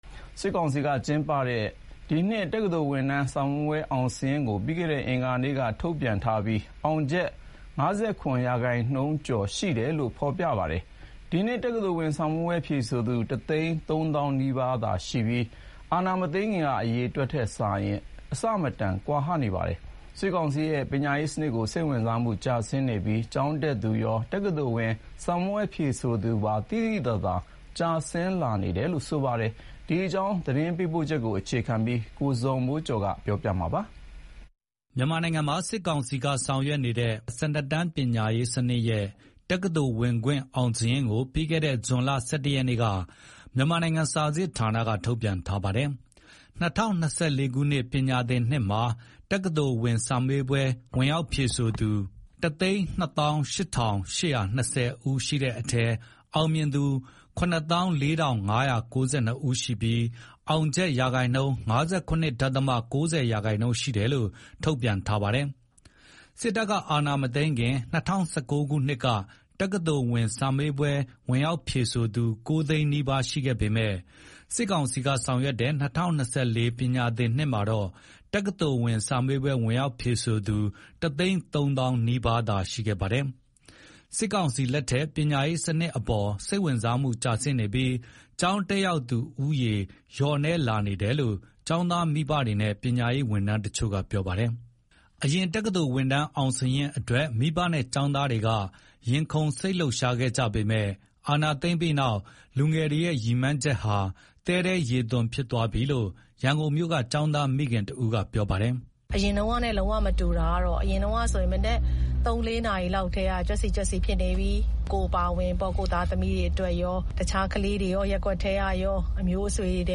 အရင်က တက္ကသိုလ်ဝင်တန်း အောင်စာရင်းအတွက် မိဘနဲ့ ကျောင်သားတွေက ရင်ခုန်စိတ်လှုပ်ရှားခဲ့ကြပေမယ့် အာဏာသိမ်းပြီးတဲ့နောက် လူငယ်တွေရဲ့ ရည်မှန်းချက်ဟာ သဲထဲရေသွန်ဖြစ်သွားပြီလို့ ရန်ကုန်မြို့က ကျောင်းသားမိခင်တဦးက အခုလို ပြောပါတယ်။
CDM မှာ ပါဝင်တဲ့ ကျောင်းသားတချို့ကတော့ တော်လှန်ရေးအင်အားစုက ဆောင်ရွက်တဲ့ အွန်လိုင်းကျောင်းကနေလည်း ကျောင်းတက်သူတွေ ရှိပါတယ်။ နိုင်ငံအတွင်း ပညာရေးကမောက်ကမ ဖြစ်နေချိန် နိုင်ငံခြားထွက်ပြီး ကျောင်းတက်ဖို့ ကြိုးစားလာသူတွေလည်းရှိတယ်လို့ လုံခြုံရေးအရေး အမည်မဖော်လိုတဲ့ ဆရာမက ပြောပါတယ်။